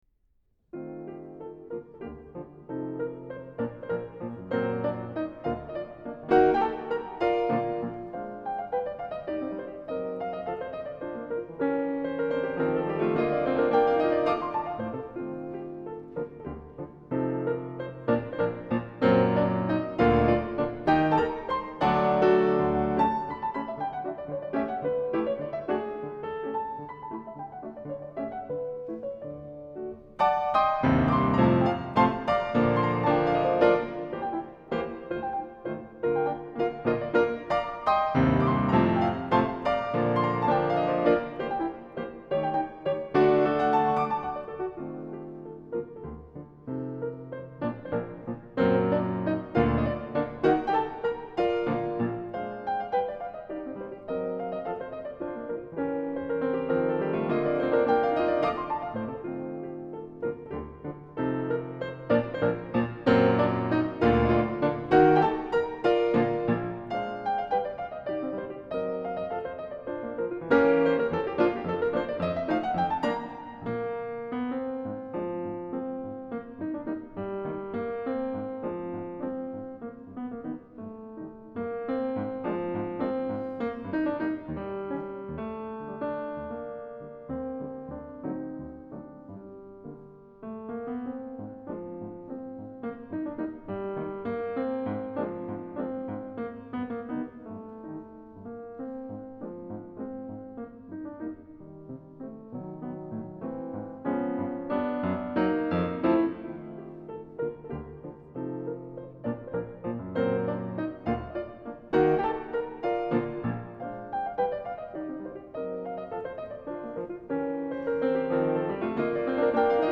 Genre : Classical